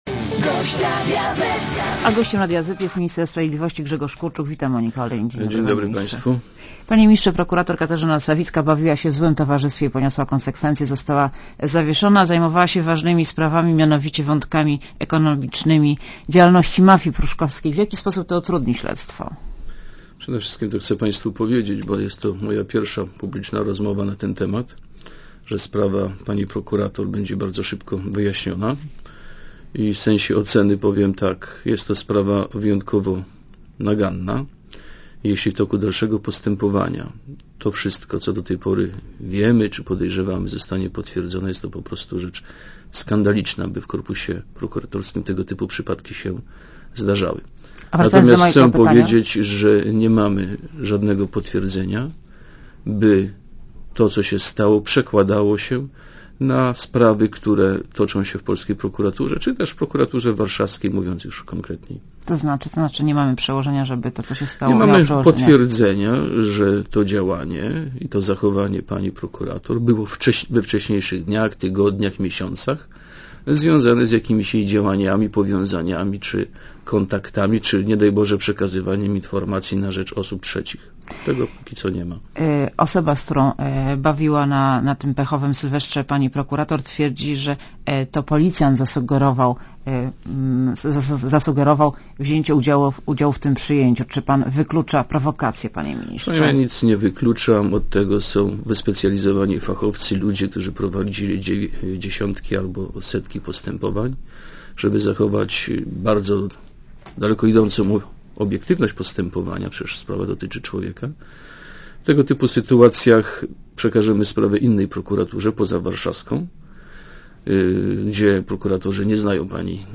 A gościem Radia Zet jest minister sprawiedliwości Grzegorz Kurczuk. Wita Monika Olejnik.
Posłuchaj wywiadu (2,57 MB) A gościem Radia Zet jest minister sprawiedliwości Grzegorz Kurczuk.